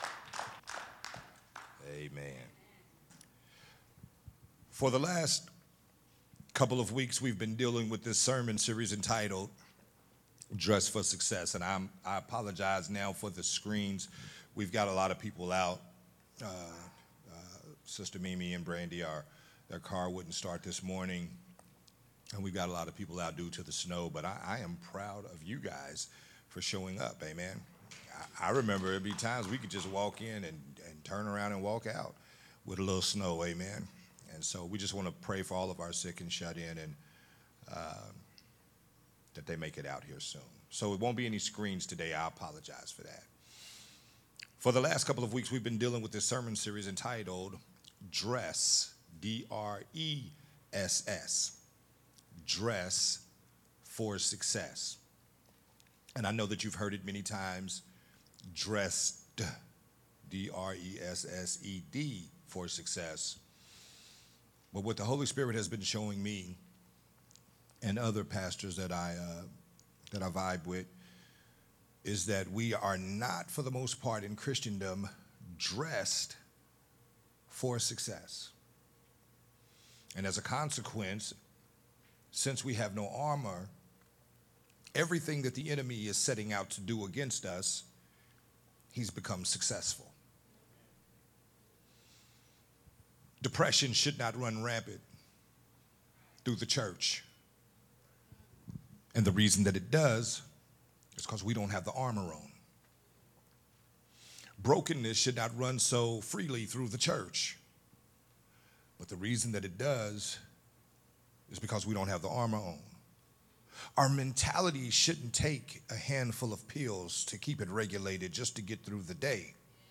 sermon series
recorded at Unity Worship Center on Sunday